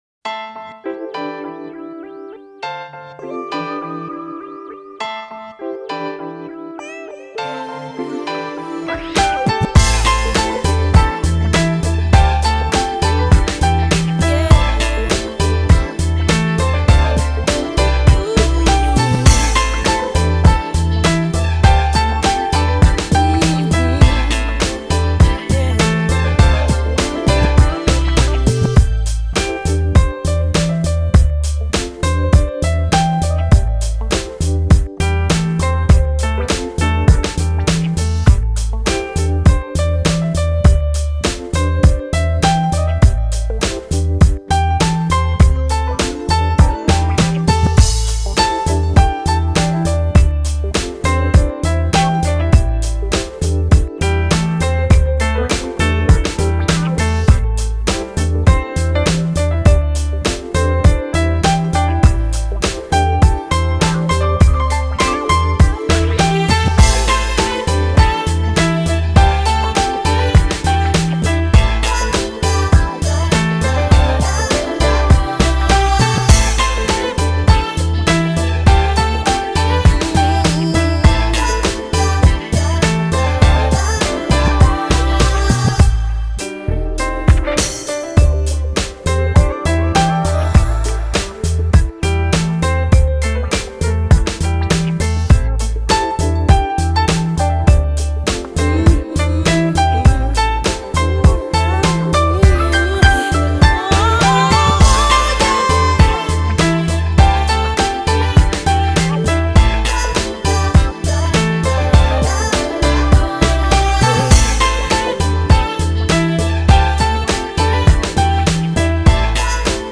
风　　格：Smooth Jazz